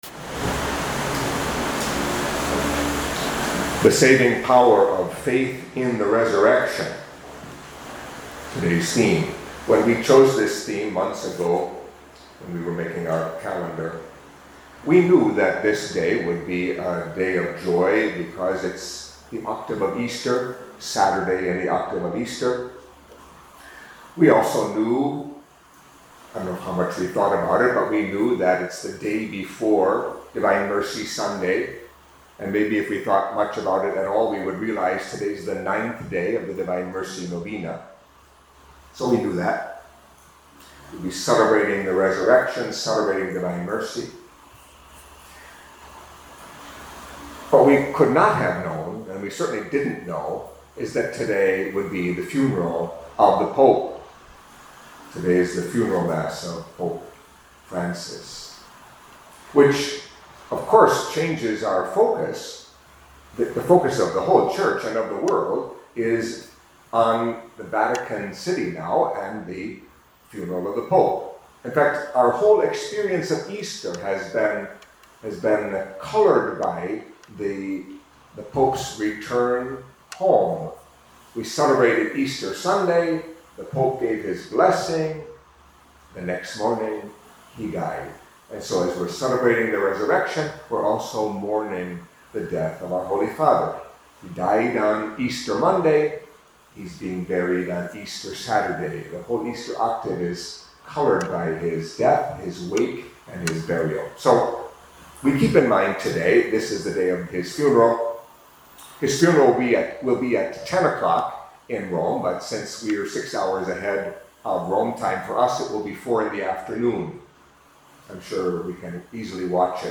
Catholic Mass homily for Saturday in the Octave of Easter